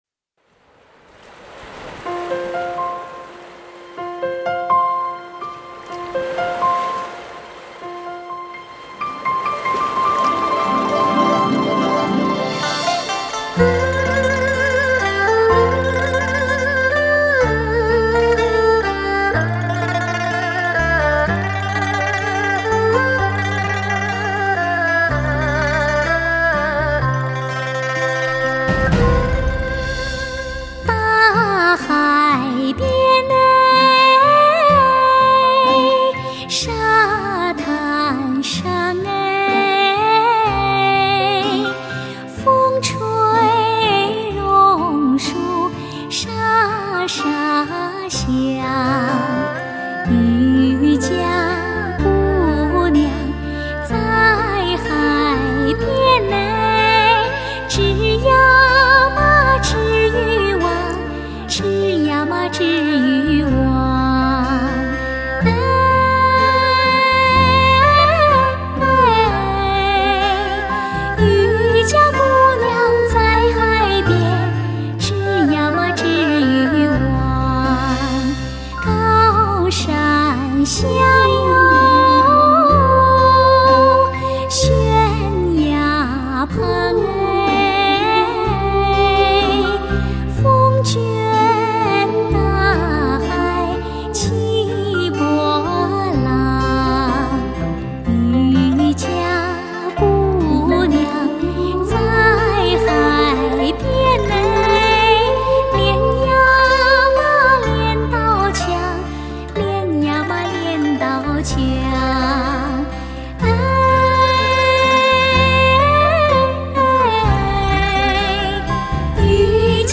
奏，深情满溢的歌唱，录音质素一流，让人耳目一新，一听再听，无法自拔。